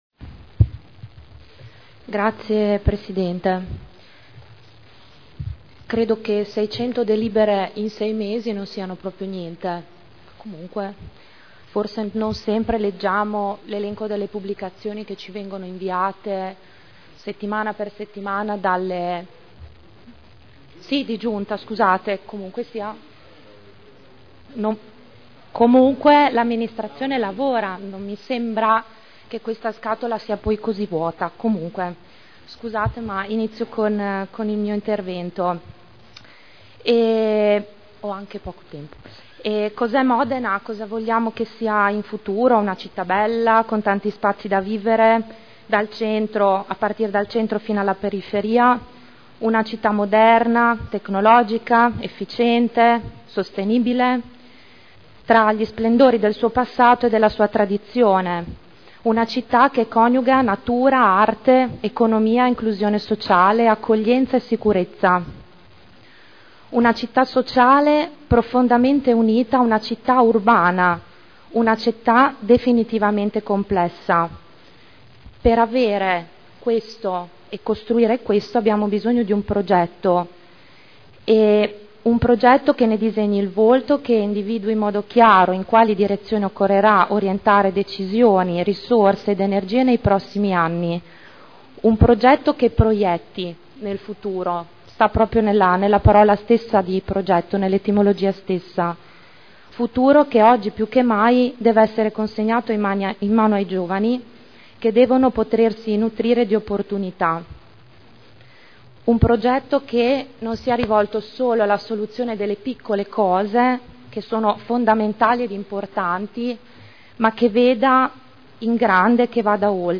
Elisa Sala — Sito Audio Consiglio Comunale